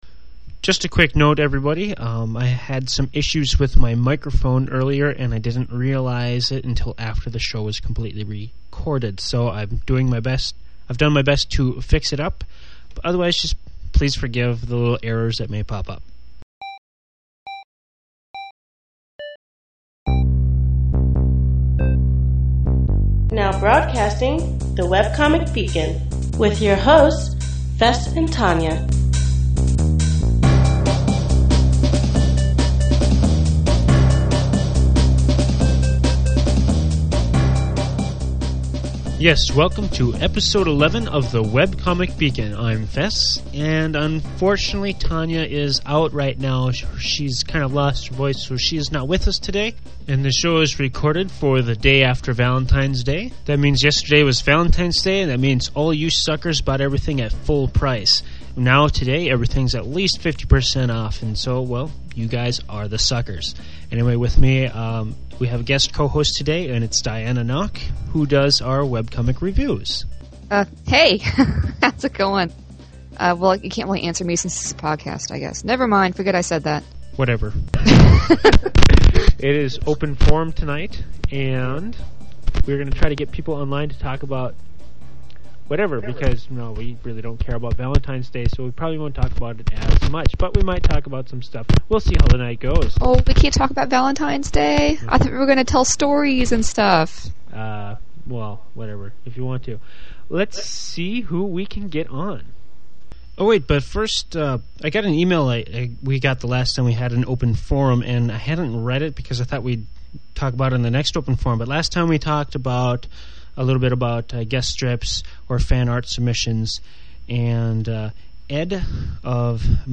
The candy is 50% off, and we had another open forum for this week’s show! We maybe mentioned Valentine’s Day, but we also talked about The Nominees of the WCCAs (vote soon!).